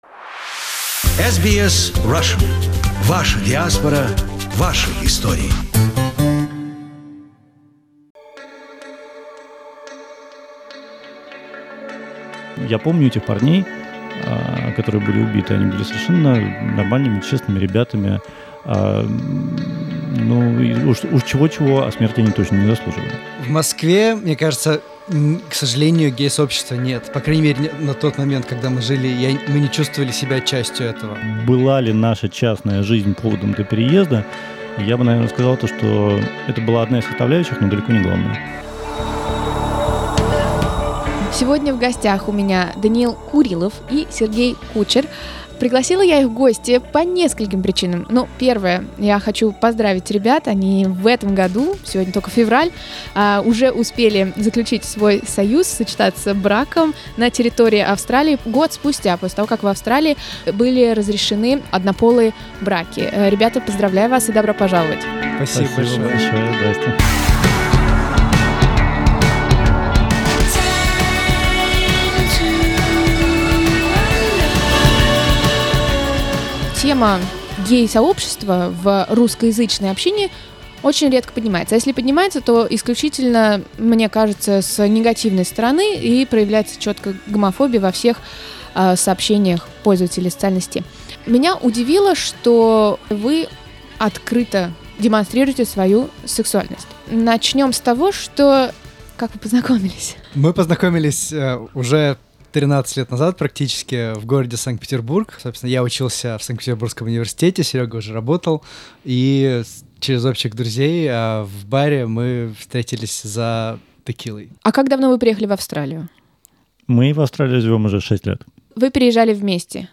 (В плеере выше представлена первая часть аудио-интервью.)